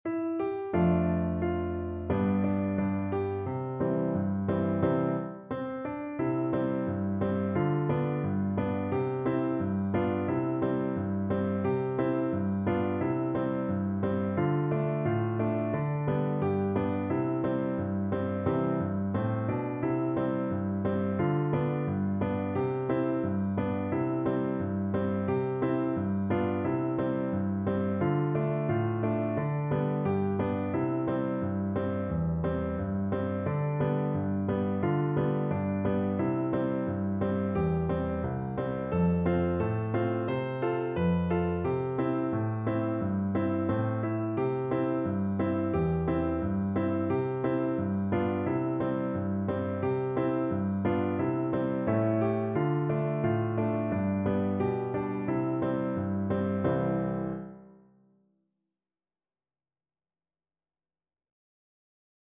Andante =c.88
4/4 (View more 4/4 Music)
C5-C6
Voice  (View more Easy Voice Music)
Traditional (View more Traditional Voice Music)
Rock and pop (View more Rock and pop Voice Music)